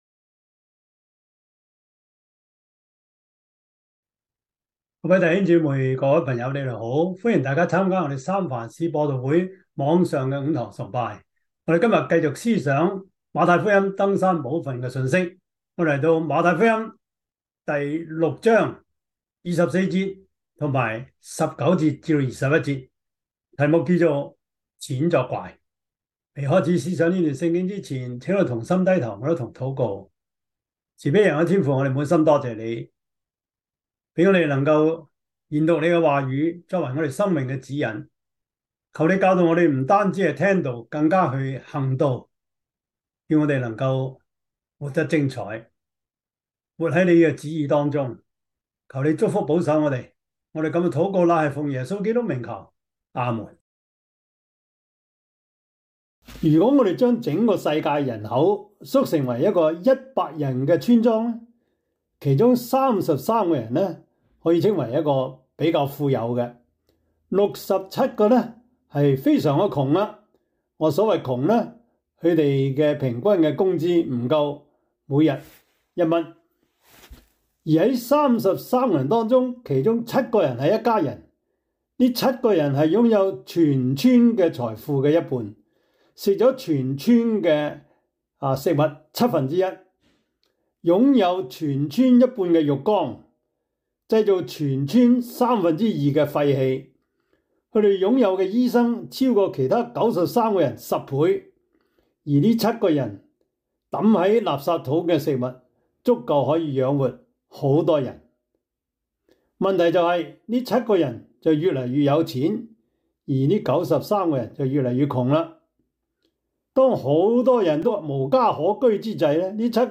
24 Service Type: 主日崇拜 馬太福音 6:19-21